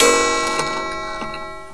Clock.wav